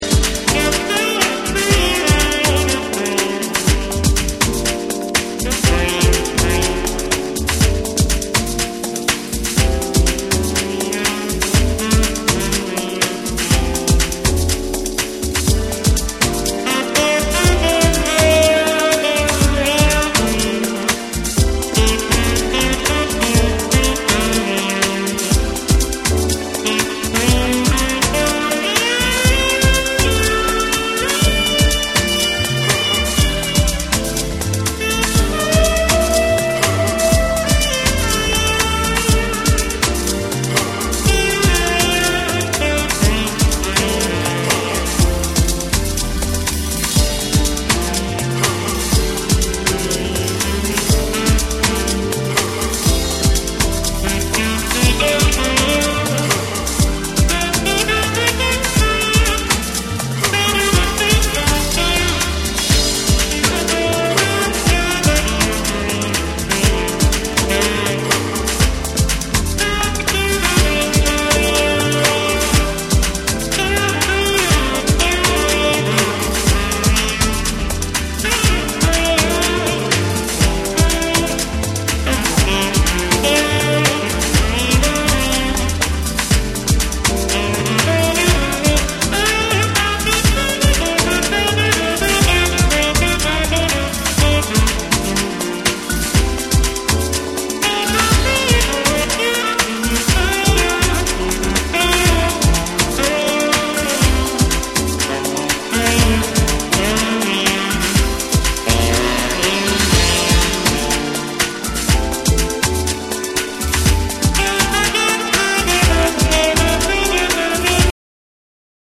ジャズ、ブルース、ゴスペル、アフロの要素を繊細に織り交ぜた、ソウルフルで美しい壮大なディープ・ハウス作品。
TECHNO & HOUSE / ORGANIC GROOVE